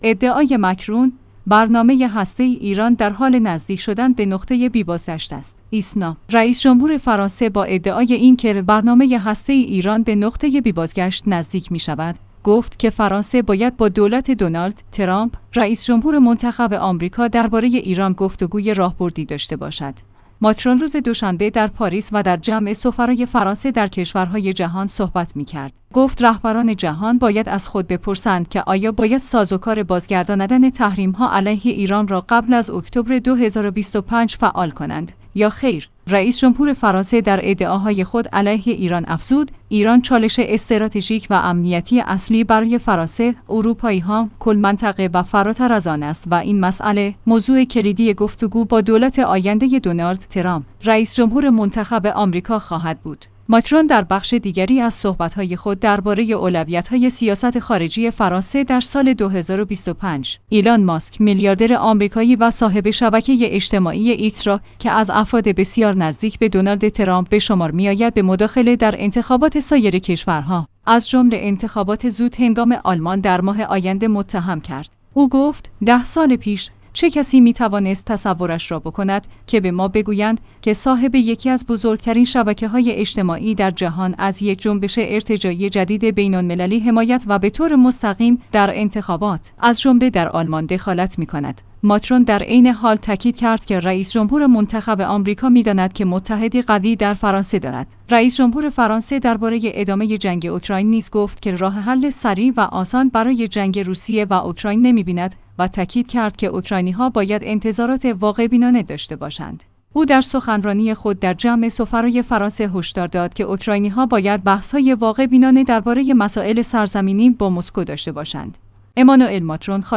ماکرون روز دوشنبه در پاریس و در جمع سفرای فرانسه در کشورهای جهان صحبت می‌کرد، گفت رهبران جهان باید